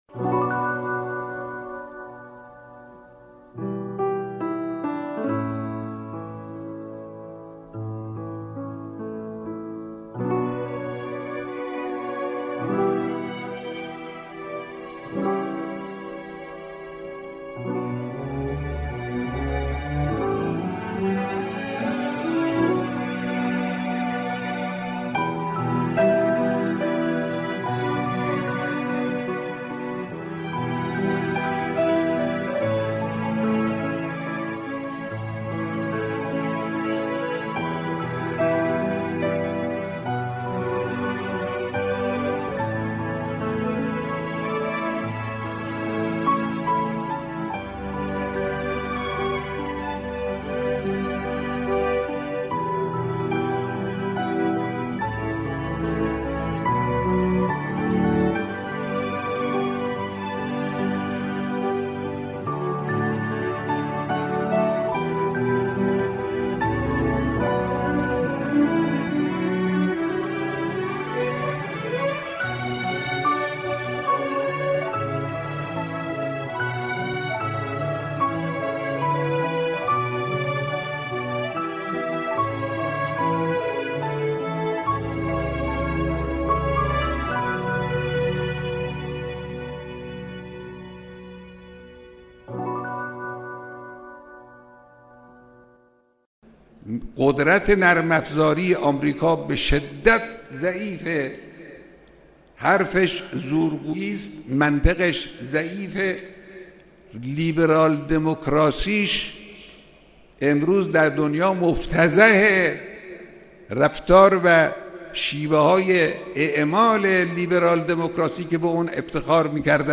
"پرتویی از آفتاب" کاری از گروه معارف رادیو تاجیکی صدای خراسان است که به گزیده ای از بیانات رهبر معظم انقلاب می پردازد.